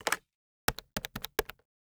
taxi_phone.wav